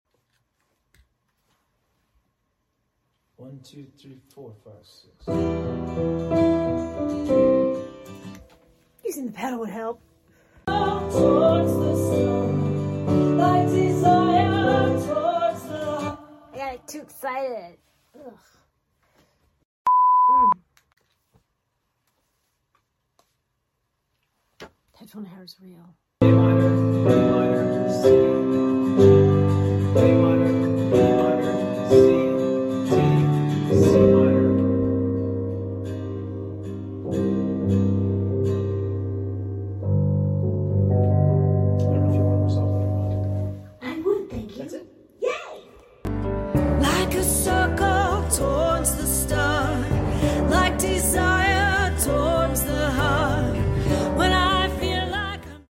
piano part
calling out the ever-changing chords